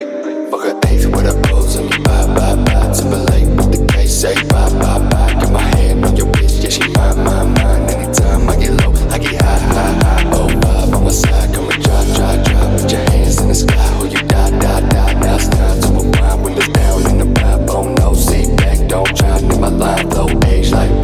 • Качество: 320, Stereo
мужской голос
спокойные
качающие
Крутой качающий рингтон